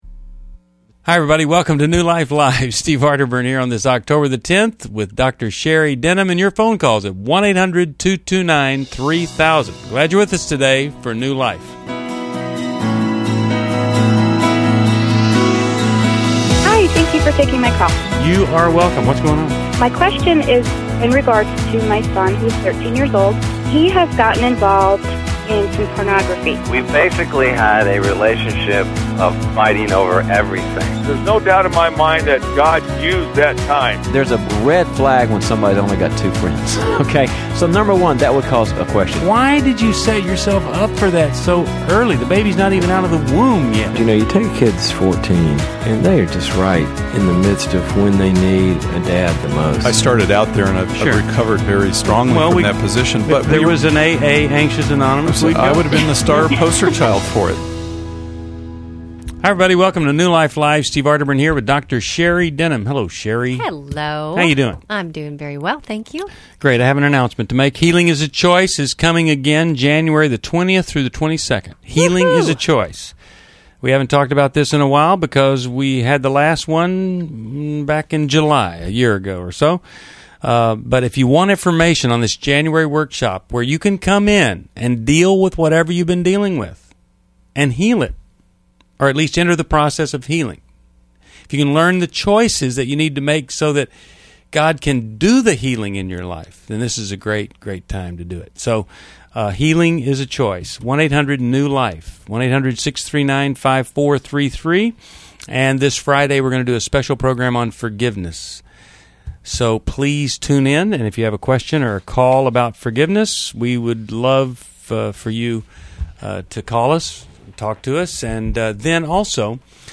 Caller Questions: 1. Should we break up after 3yrs if we’re not married yet? 2.